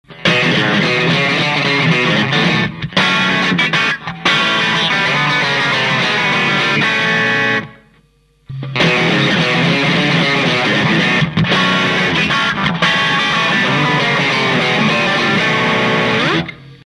曖昧な表現ですが「突き刺す感じ」が増して